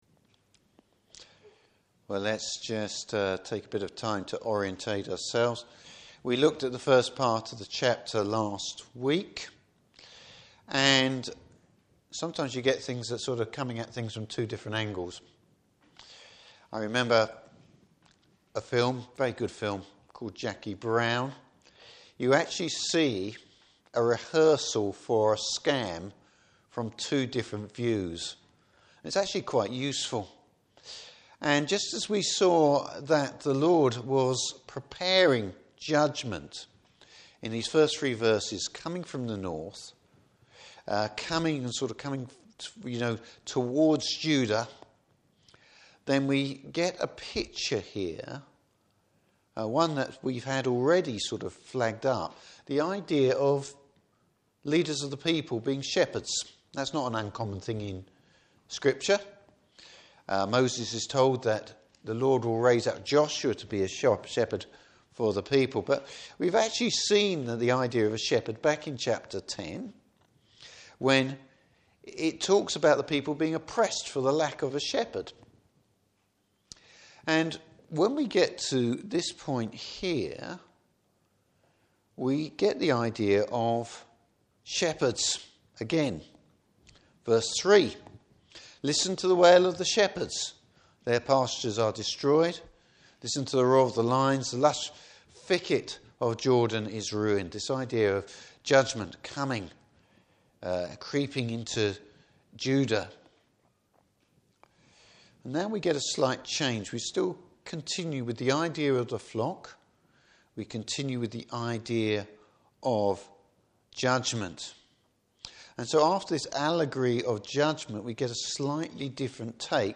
Service Type: Evening Service Will The Lord’s people recognize his leader?